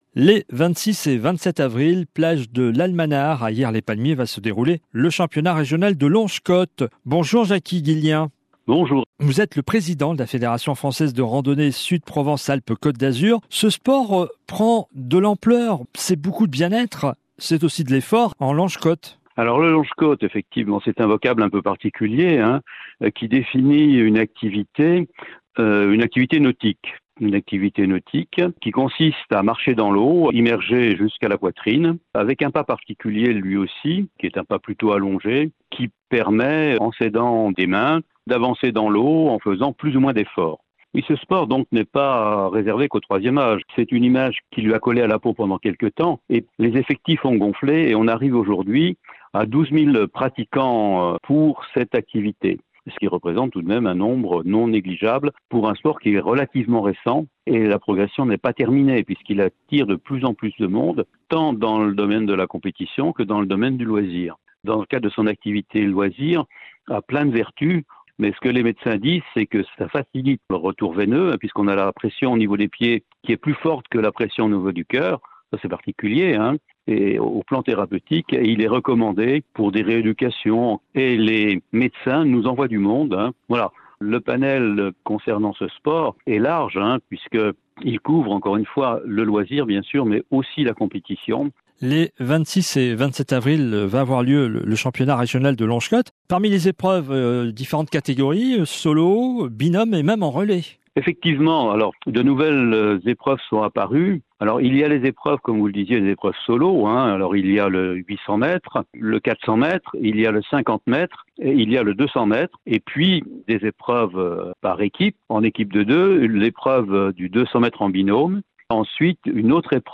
L’interview